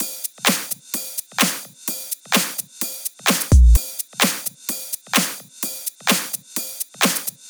VDE 128BPM Close Drums 1.wav